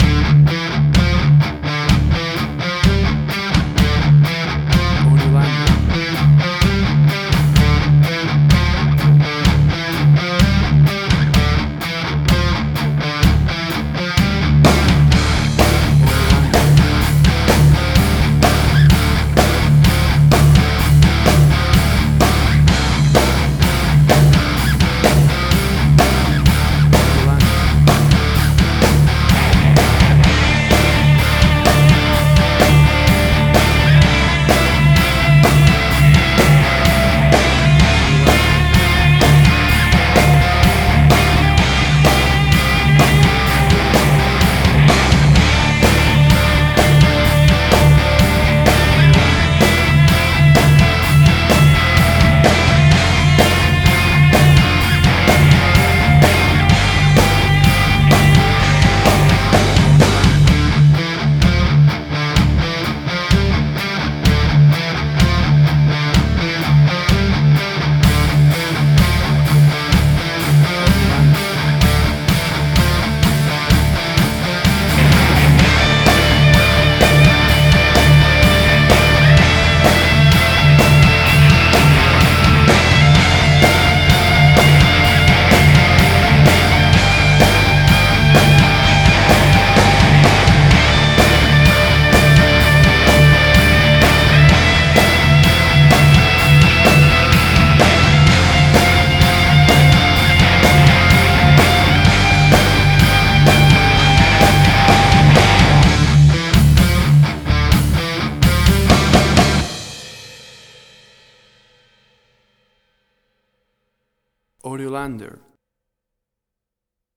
Hard Rock
Heavy Metal.
Tempo (BPM): 126